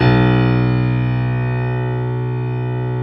55p-pno06-B0.wav